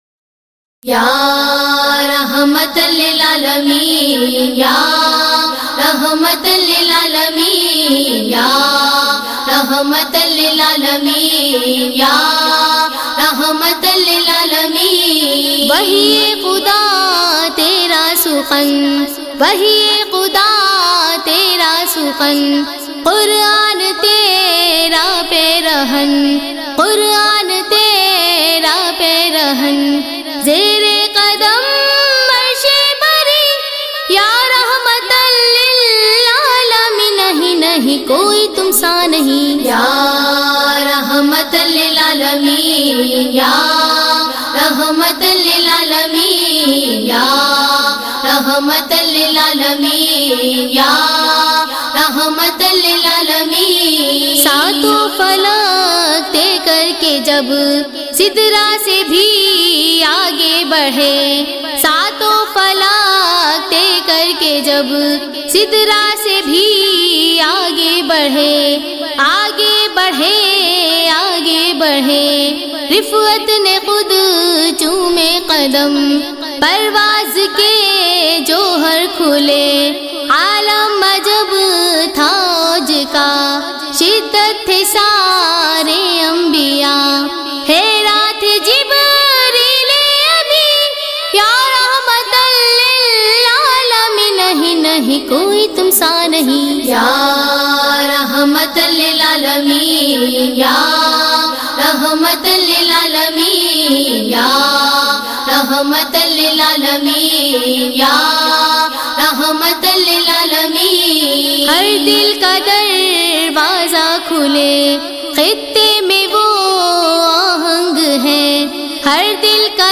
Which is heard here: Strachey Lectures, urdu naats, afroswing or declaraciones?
urdu naats